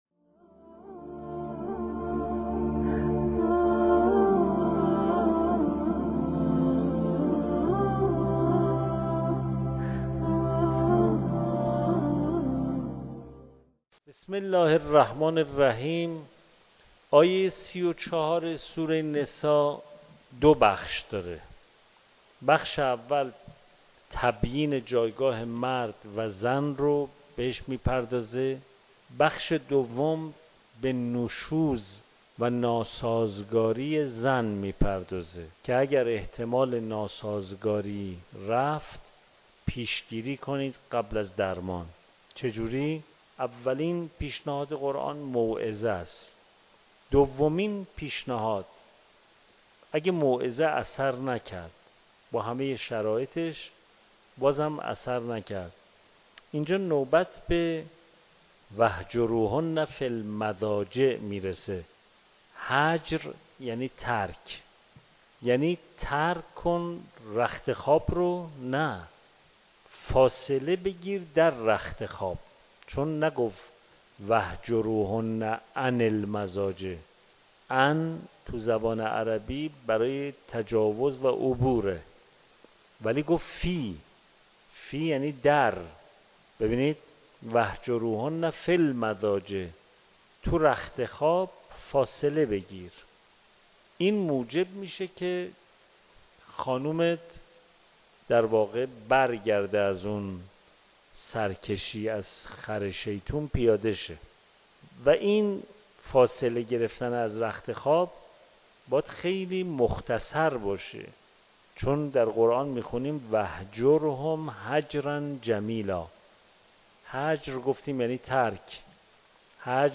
مکان: دارالتفسیر حرم مطهر رضوی